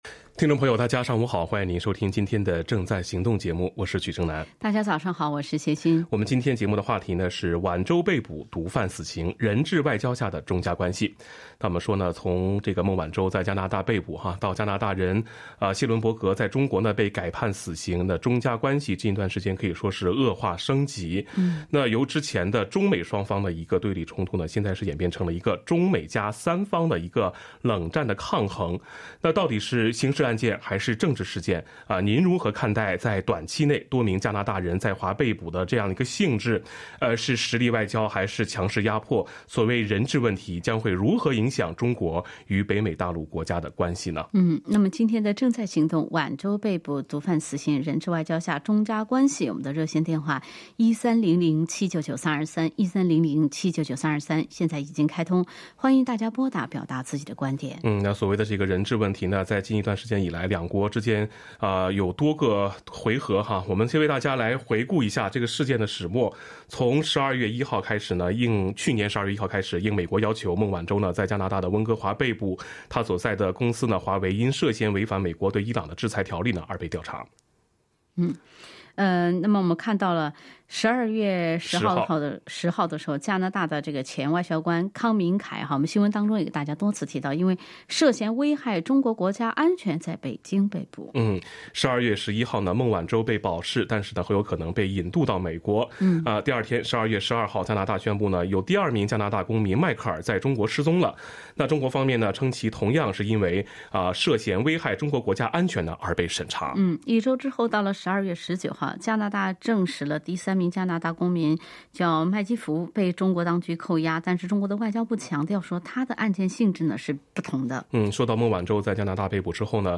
action_talkback_jan_23.mp3